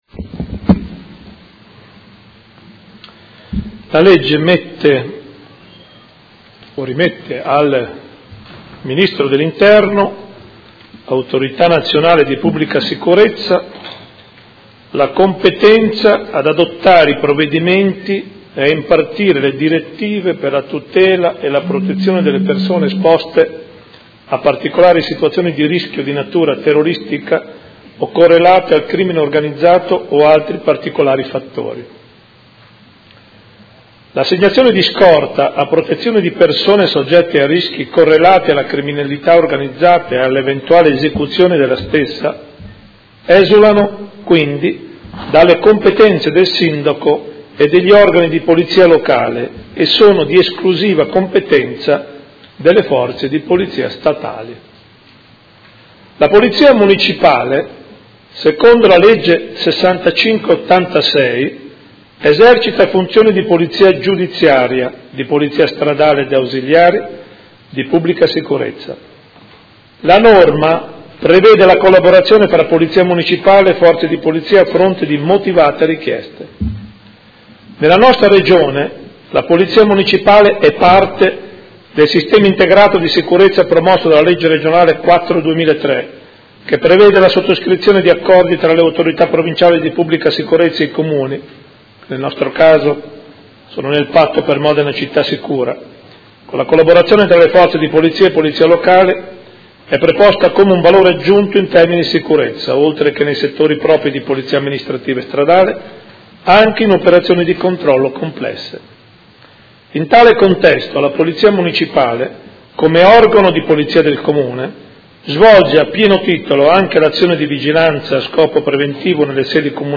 Seduta del 10/03/2016.
Sindaco